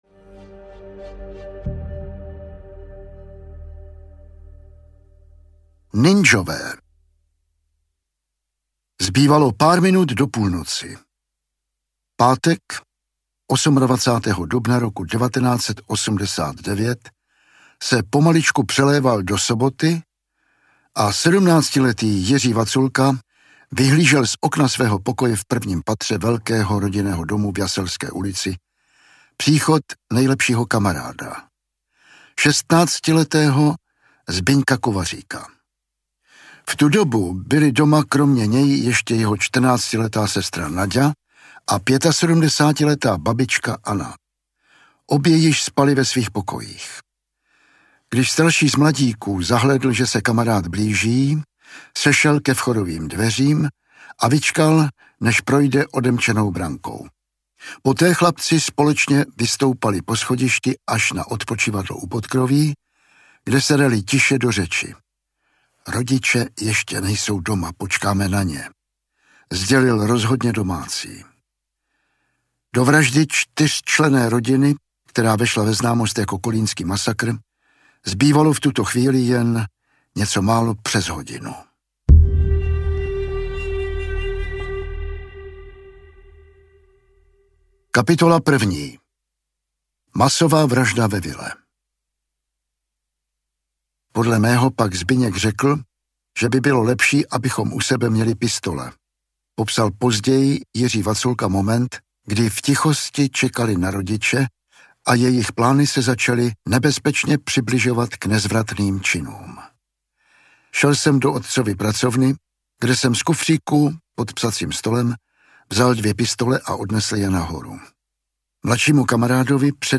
Dva na vraždu audiokniha
Ukázka z knihy
• InterpretJan Vlasák